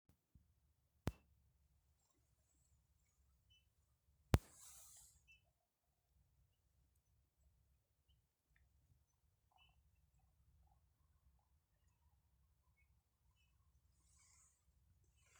снегирь, Pyrrhula pyrrhula
Ziņotāja saglabāts vietas nosaukumsBauskas nov Vecumnieku pag